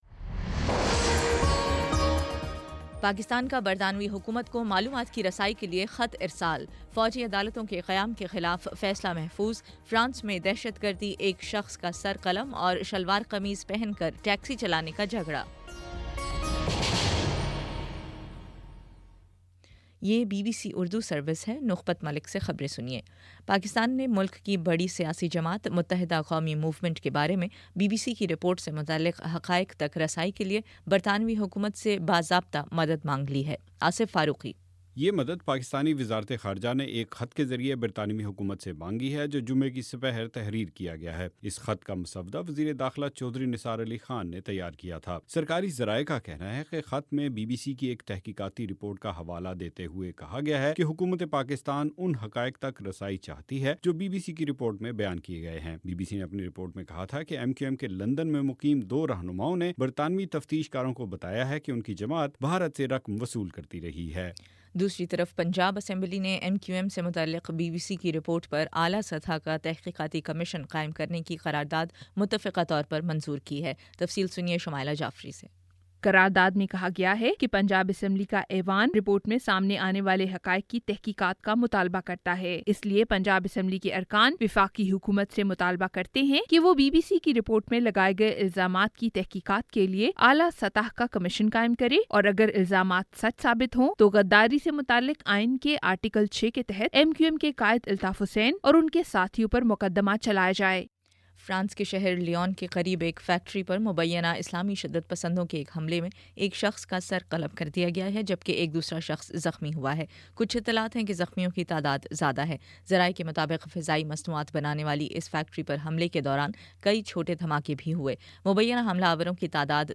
جون 26: شام پانچ بجے کا نیوز بُلیٹن